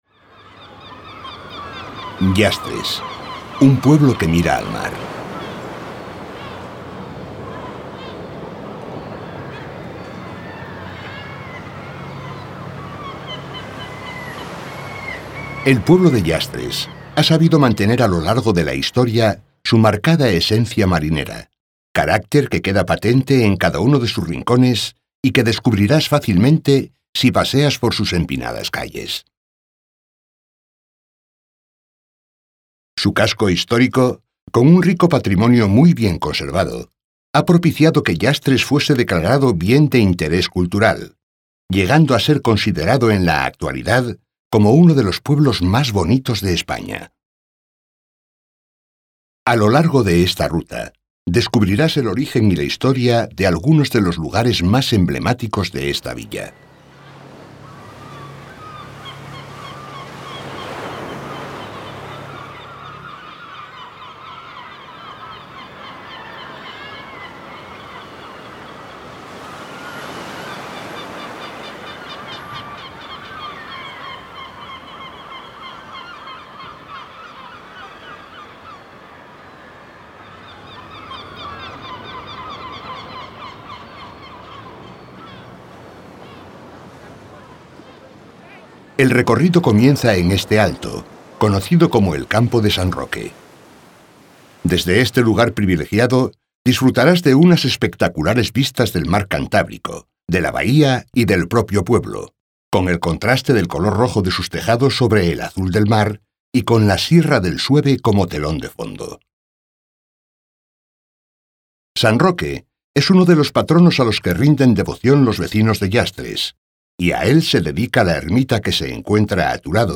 1. Audioguía: Llastres, un pueblo que mira al mar